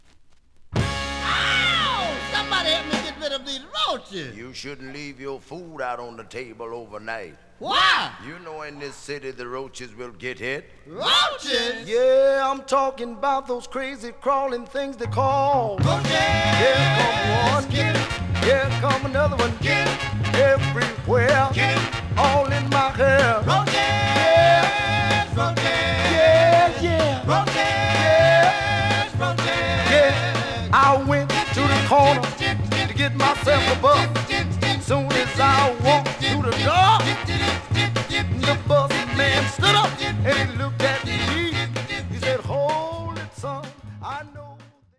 The audio sample is recorded from the actual item.
●Genre: Funk, 70's Funk
●Record Grading: VG~VG+ (傷はあるが、プレイはおおむね良好。Plays good.)